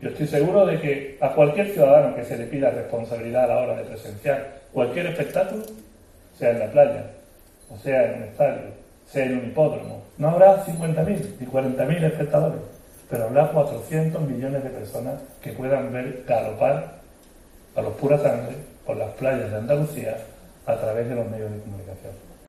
Juan Marín, vicepresidente de la Junta de Andalucía